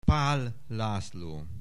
Aussprache Aussprache